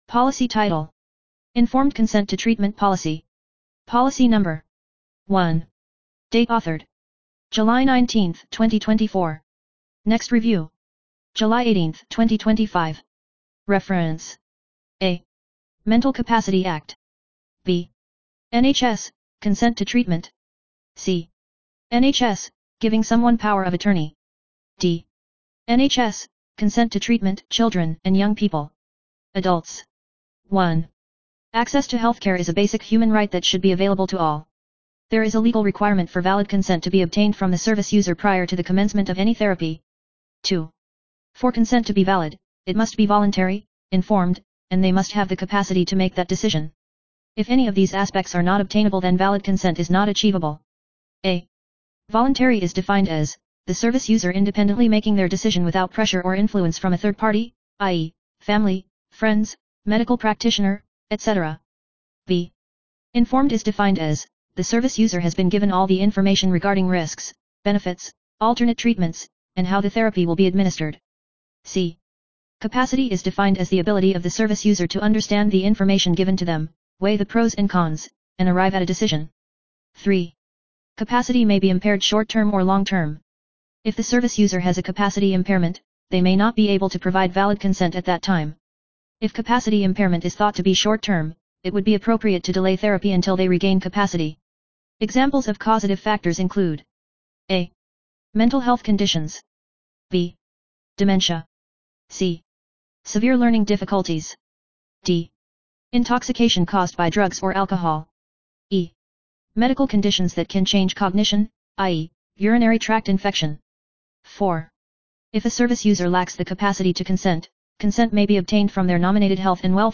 Narration of informed consent policy